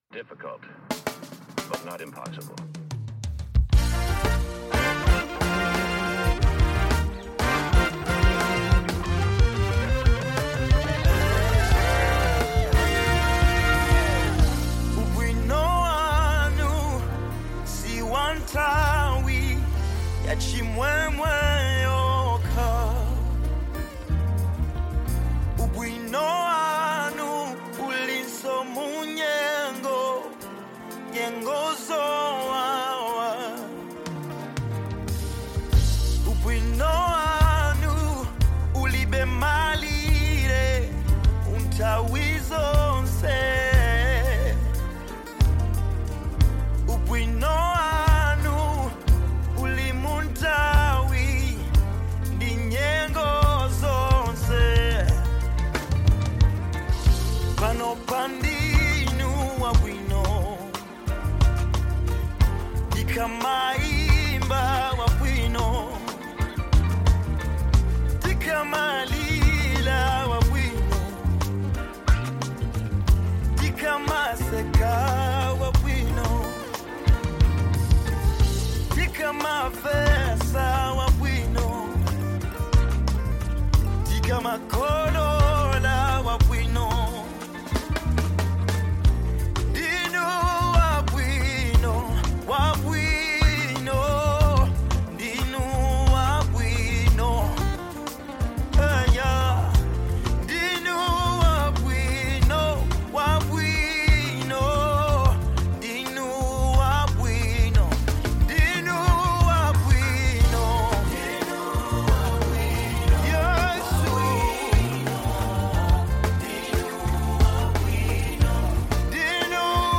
Tanzanian gospel singer
inspirational song